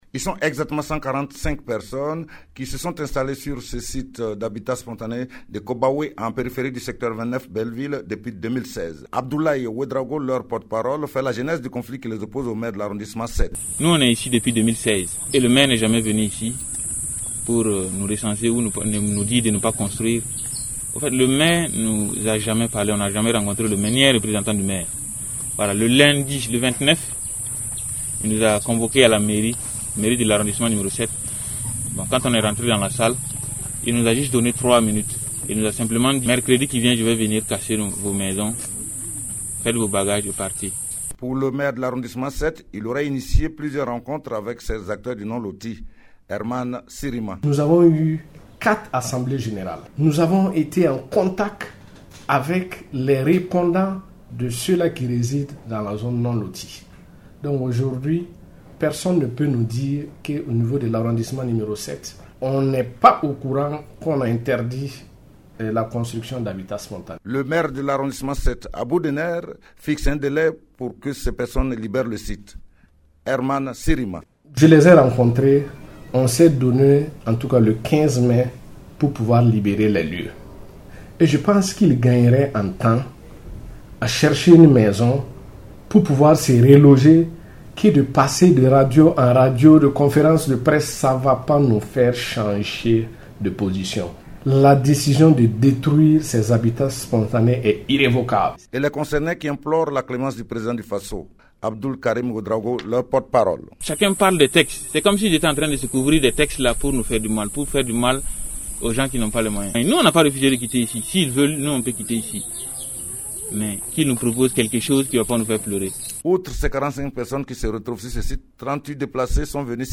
Ils sont une centaine de personnes qui sont installés en périphérie du secteur 29 Belleville de Bobo-Dioulasso sans autorisation et qui sont menacés d’être déguerpis le 15 mai prochain. Ces riverains ont convié la presse nationale à une conférence de presse sur leur site ce mercredi.